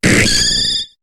Cri de Yanma dans Pokémon HOME.